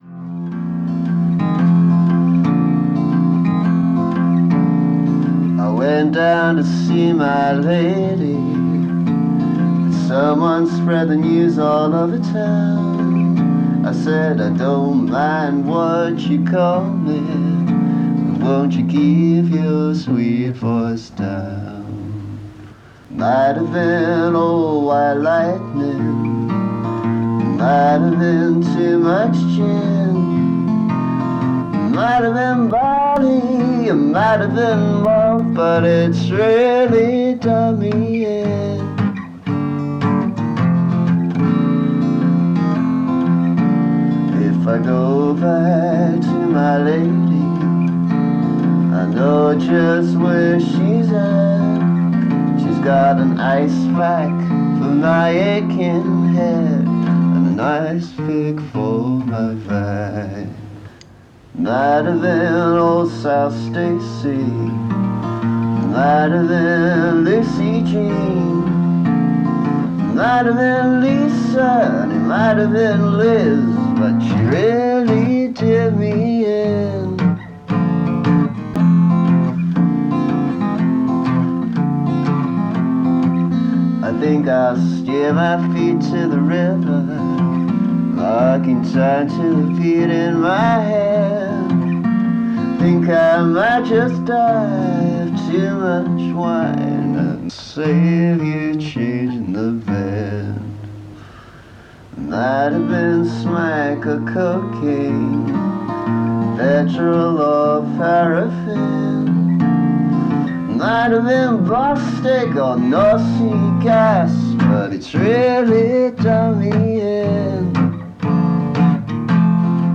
Ancient version remastered (somewhat).